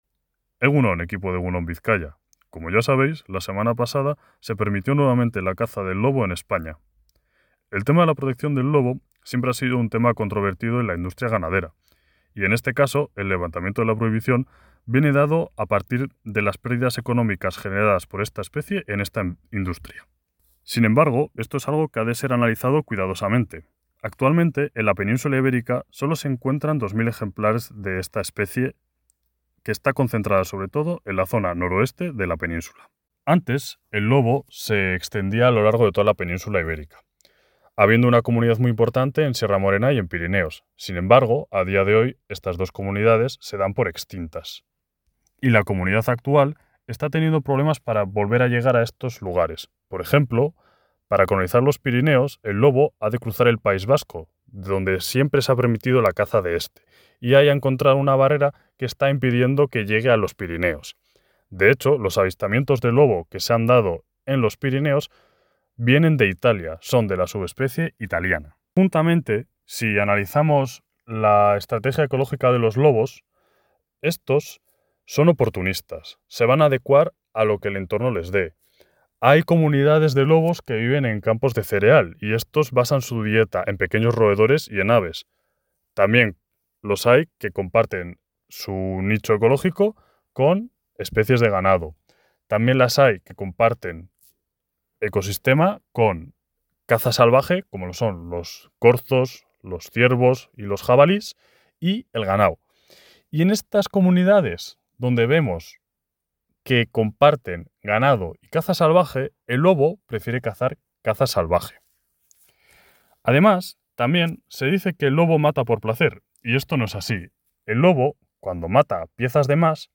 LOGOS Elkartea ha intervenido en la sección Vamos a contar mentiras para valorar el levantamiento de la prohibición de cazar lobos.